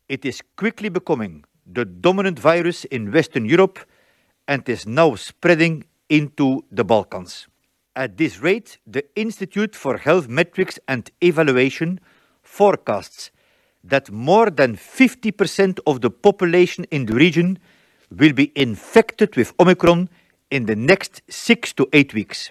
The WHO’s Europe Director Hans Kluge says the variant represents a west to east tidal wave sweeping across the region: